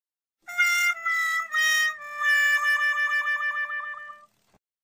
whaawhaa.ogg